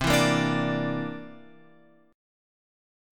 C 9th